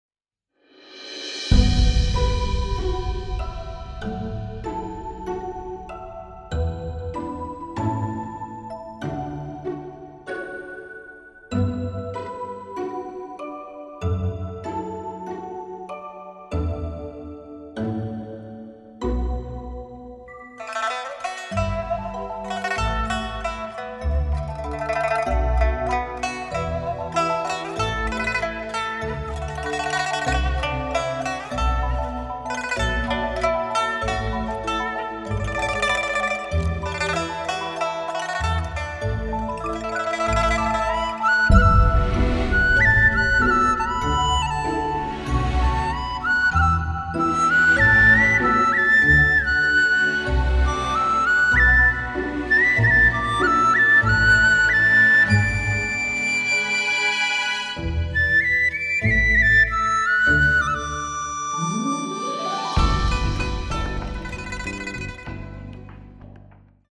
注：线上教学背景音乐，如需要，请点击附件自行下载。（仅支持ＰＣ版本下载）
少儿中国舞音乐.mp3